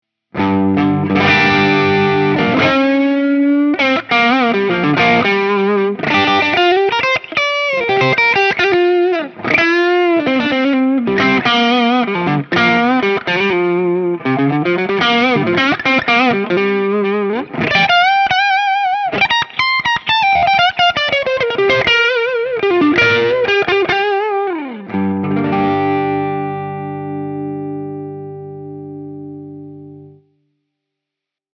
Tutte le clip audio sono state registrate con amplificatore Fender Deluxe e una cassa 2×12 con altoparlanti Celestion Creamback 75.
Clip 6 – Les Paul, Classic Gain, clean amp
Chitarra: Gibson Les Paul (Pickup al manico)
Over Drive: 5/10